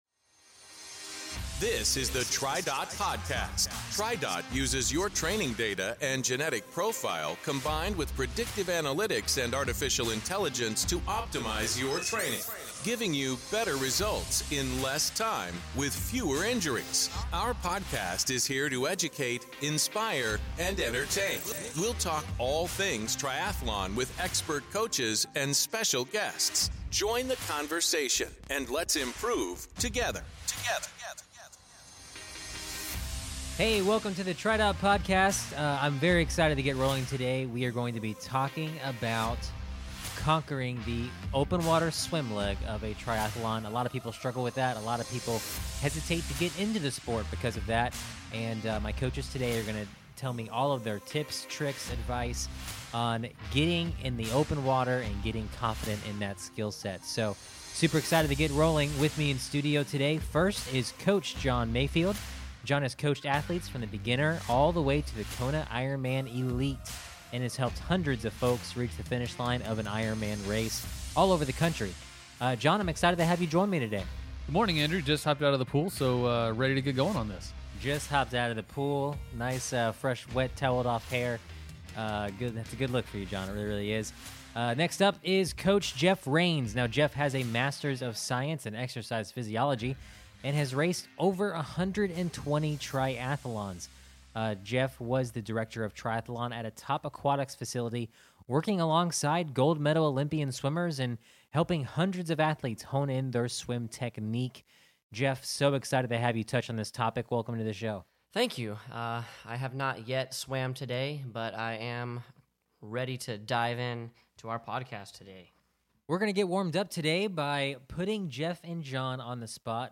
So super excited to get rolling with me in studio today.